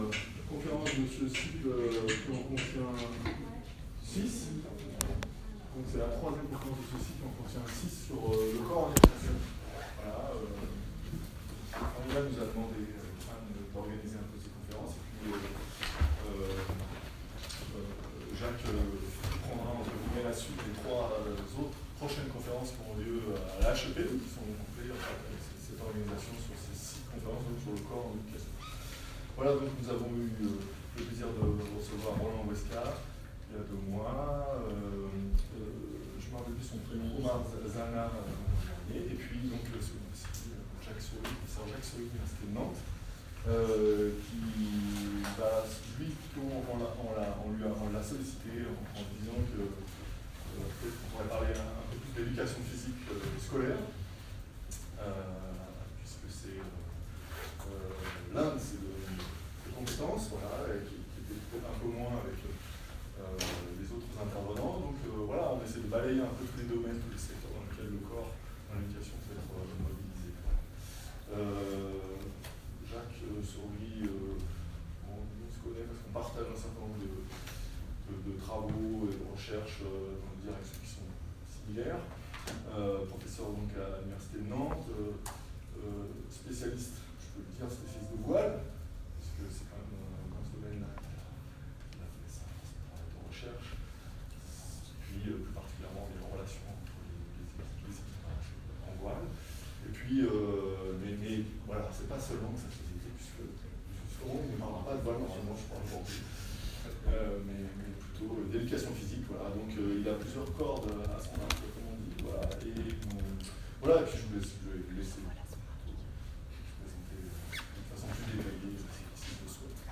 Conférence OBSEF